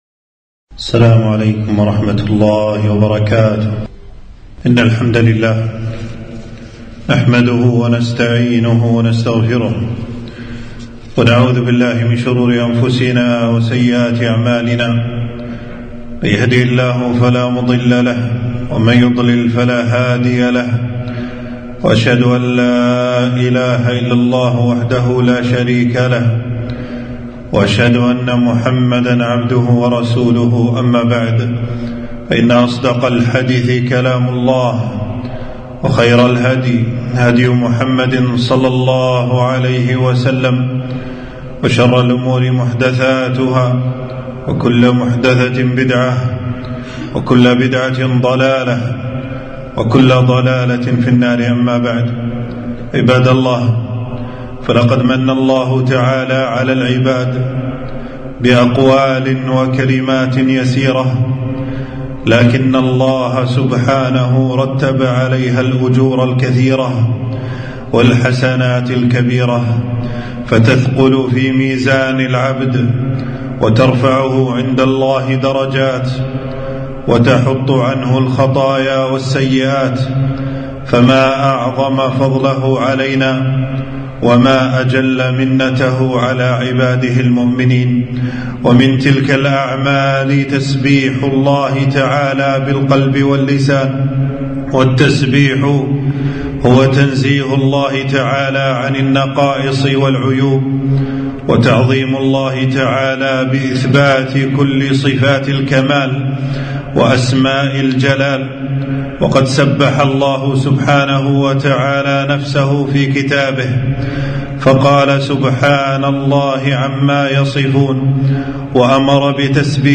خطبة - تسبيح الله يرفع الدرجات ويحط الخطيئات